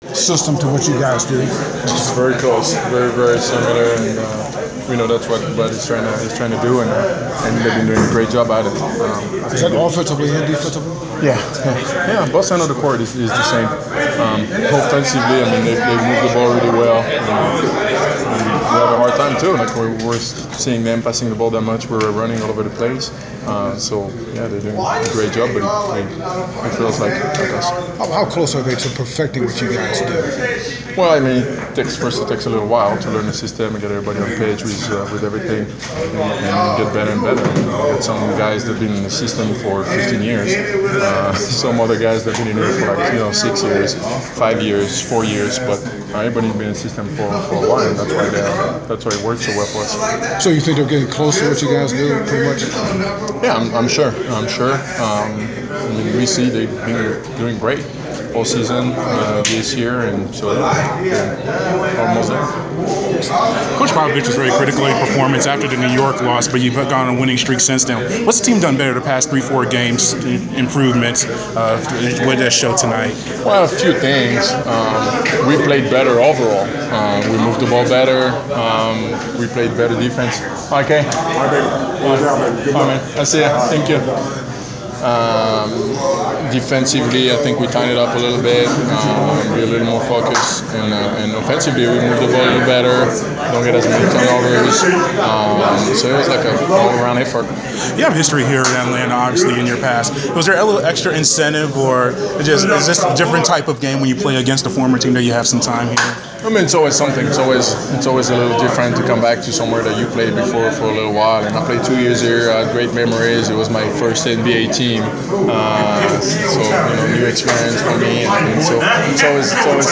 Inside the Inquirer: Postgame interview with San Antonio Spurs’ Boris Diaw (3.22.15)